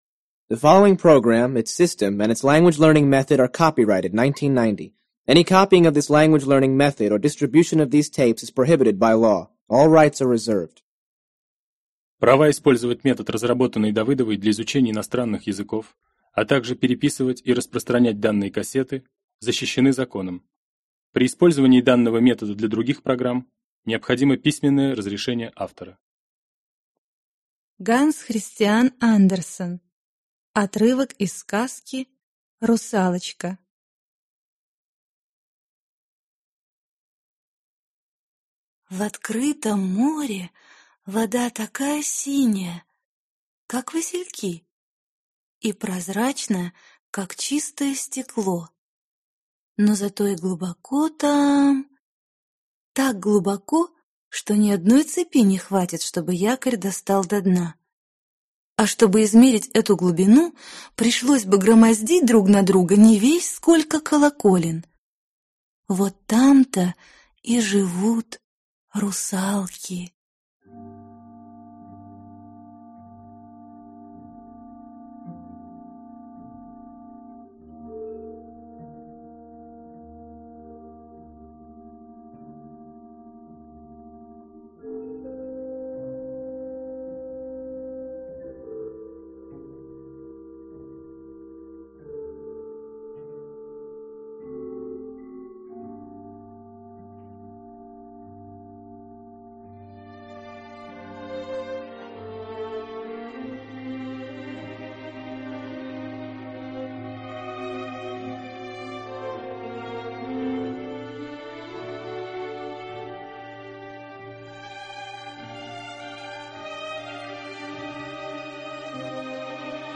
Аудиокнига Сложный литературный английский.